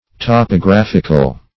\Top`o*graph"ic*al\, [Cf. F. topographique.]